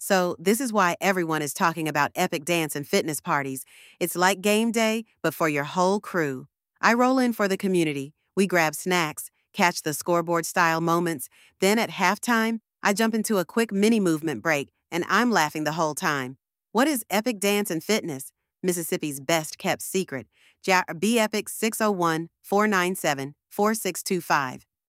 Voiceover over b-roll: studio seating setup, TV/scoreboard-style shots (no NFL branding), class energy cutaways, snack prep closeups (generic). Message: come for community, halftime-style mini movement, fun vibes.